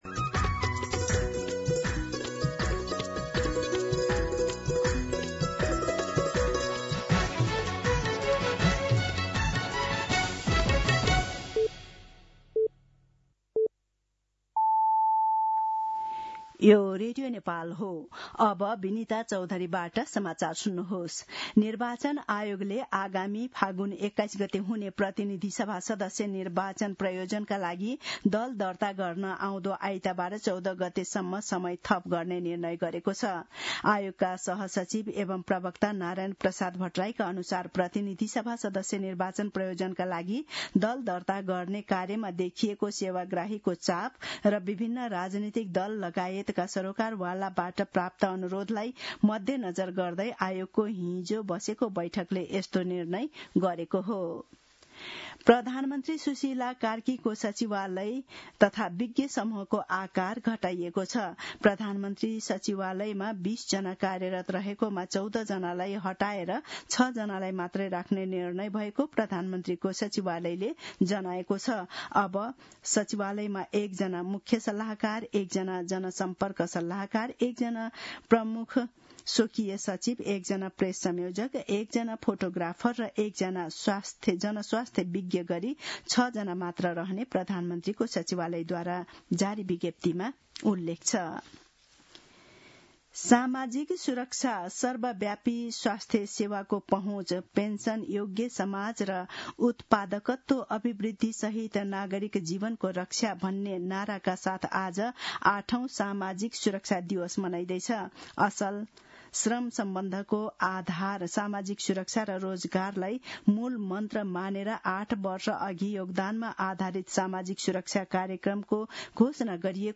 दिउँसो १ बजेको नेपाली समाचार : ११ मंसिर , २०८२
1-pm-news-8-11.mp3